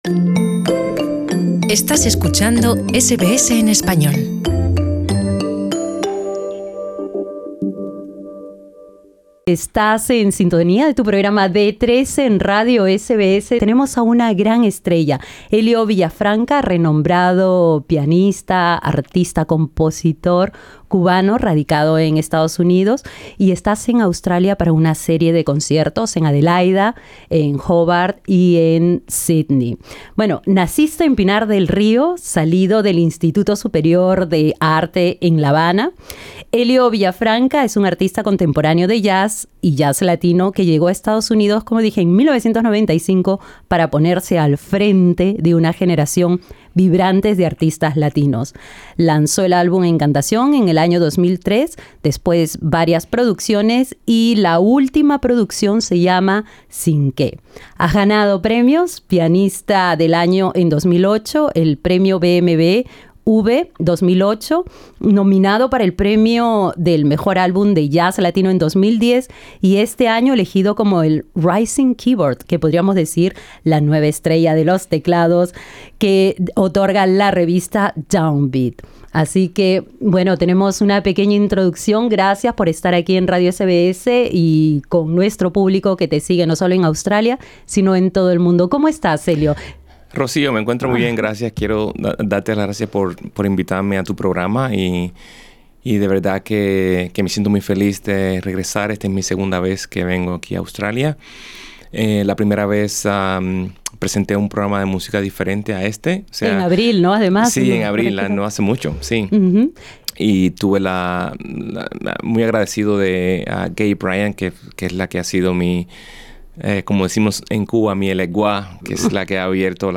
Escucha nuestra entrevista en el enlace de arriba Y en los siguientes de abajo, sus composiciones.